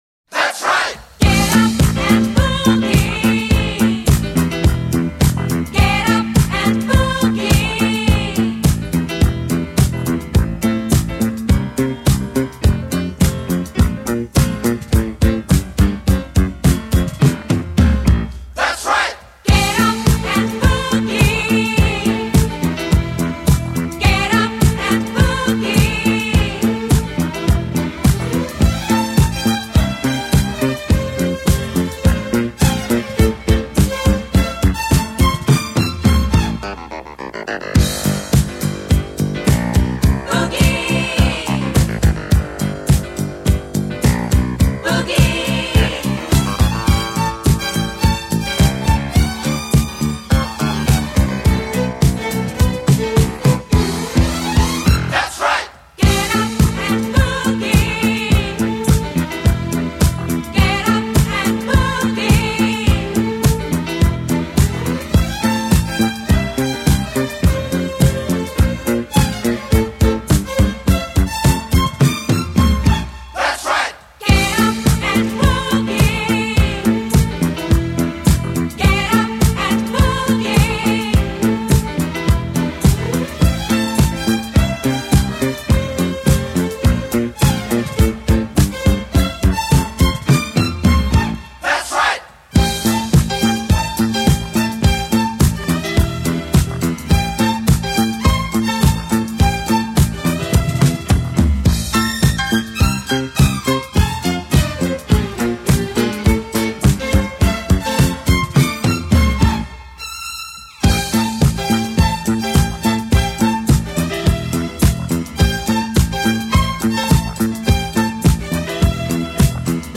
早年的迪斯可舞曲
展现出来轻快、简洁、节奏明确、旋律优美！ 这组合唱团的歌曲特色在歌词极短，重覆最多， 几近创下了西洋歌曲之最。